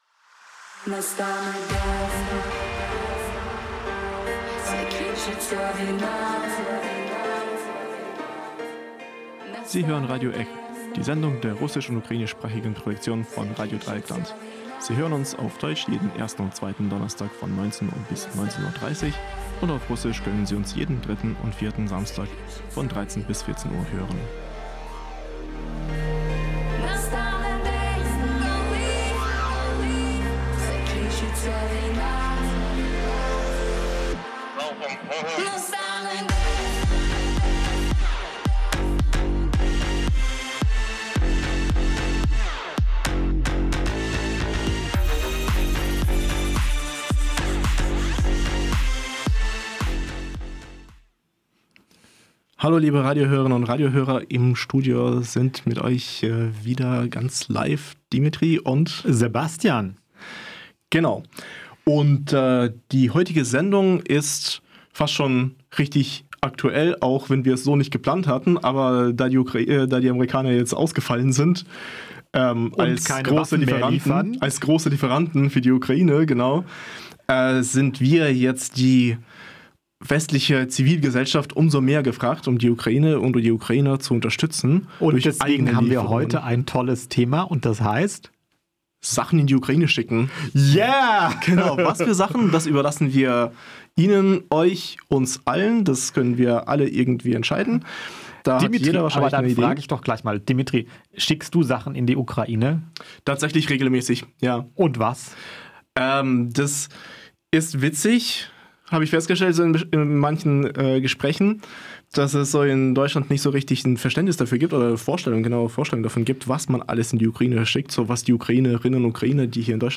Rede & Aufruf Feministische Aktion 8. März: 7:14
Grußworte Feminism Unstoppable München: 0:53